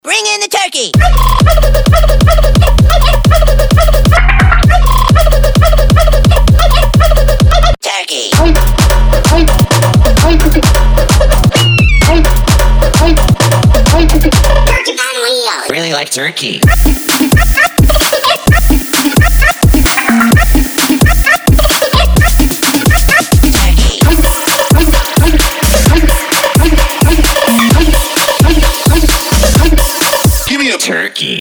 • Качество: 320, Stereo
Electronic
Trap
Дикие крики птицы под бэйс-музыку!)